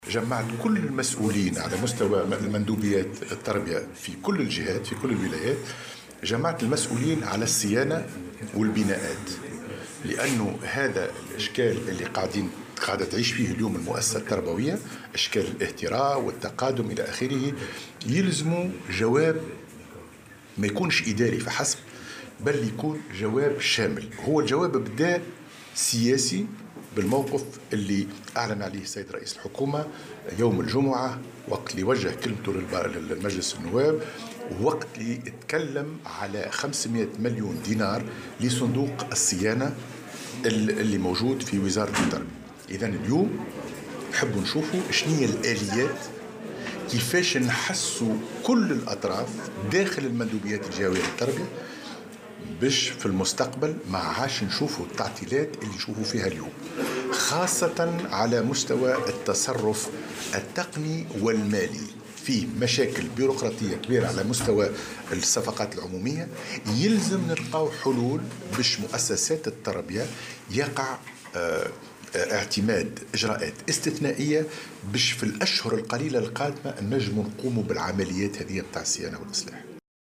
وأضاف الوزير على هامش إشرافه اليوم الاثنين بالمنستير على افتتاح أشغال الملتقى الوطني للكتاب العامين بالمندوبيات الجهوية للتربية، أن رئيس الحكومة يوسف الشاهد أعلن عن تخصيص اعتمادات قيمتها 500 مليون دينار لصيانة المؤسسات التربوية، مشيرا إلى أنه سيتم العمل على تلافي التعطيلات خاصة على مستوى التصرف التقني والمالي وتجاوز المشاكل البيروقراطية المتعلقة بالصفقات العمومية واعتماد إجراءات استثنائية لانطلاق أشغال الصيانة والإصلاح خلال الأشهر القليلة القادمة.